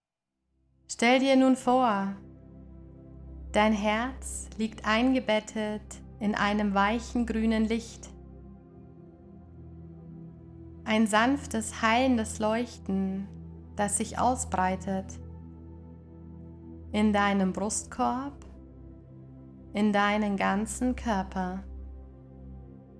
• Format: Geführte Meditationen (Audio-Dateien)